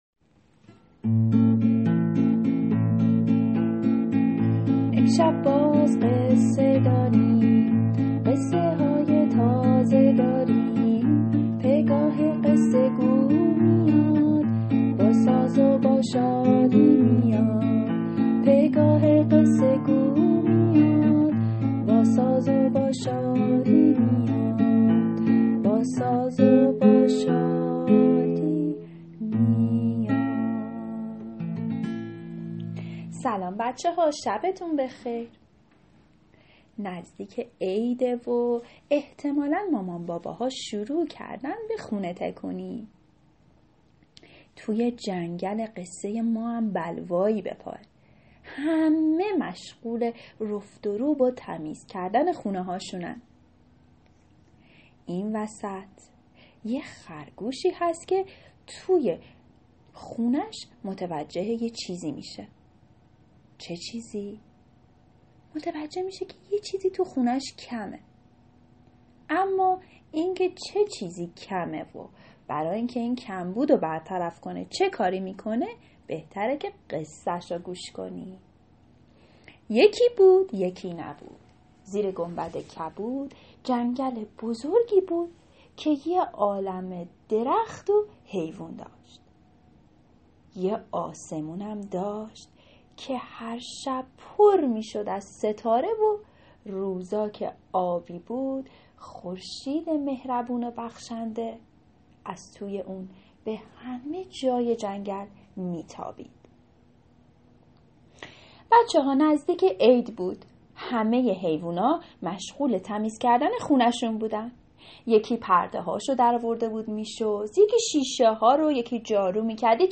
قصه صوتی کودکان دیدگاه شما 802 بازدید